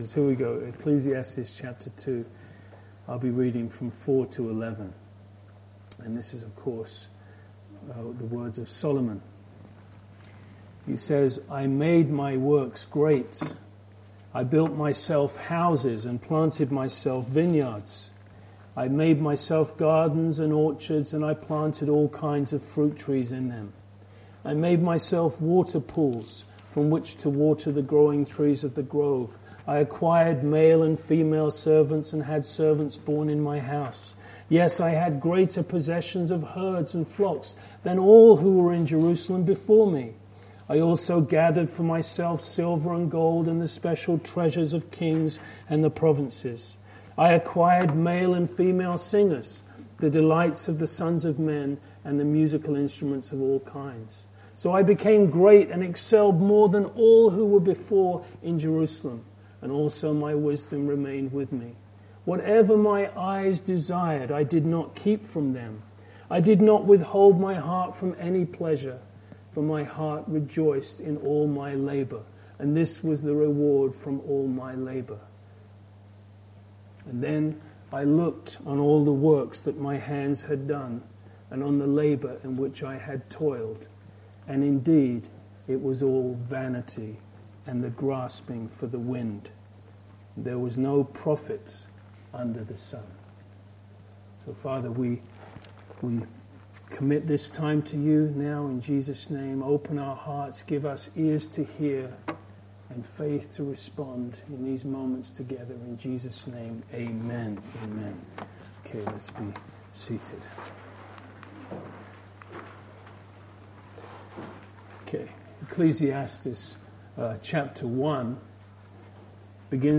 This sermon leads us to the final great conclusion and answer.